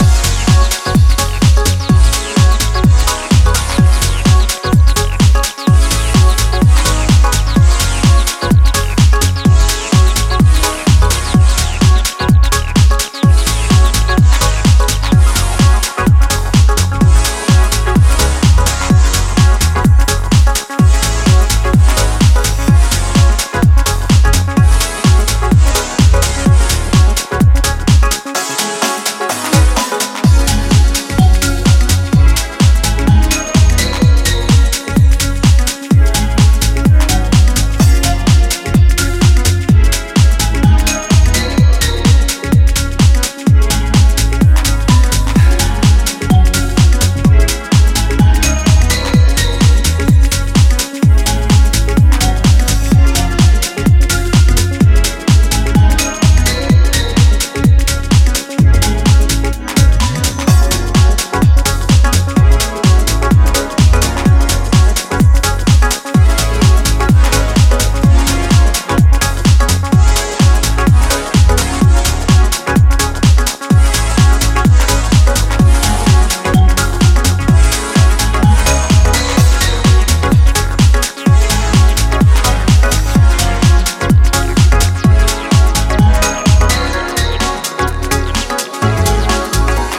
ジャンル(スタイル) HOUSE / TECH HOUSE